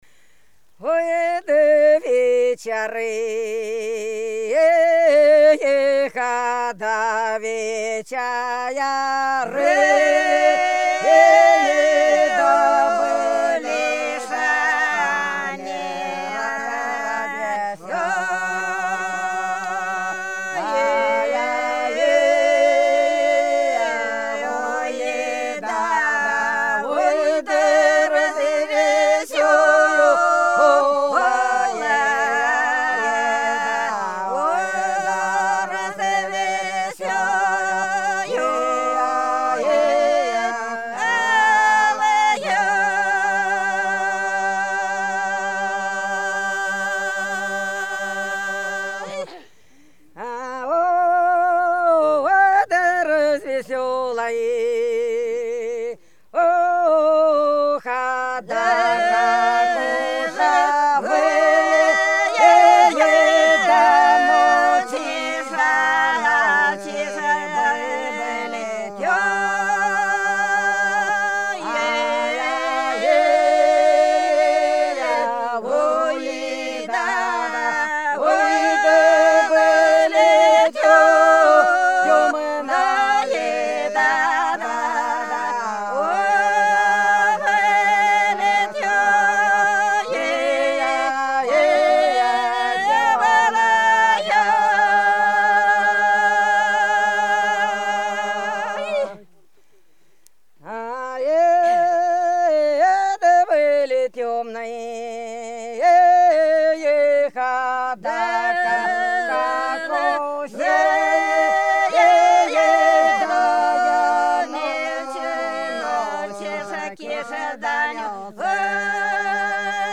Белгородские поля (Поют народные исполнители села Прудки Красногвардейского района Белгородской области) Ой, да вечеры, да вечеры - протяжная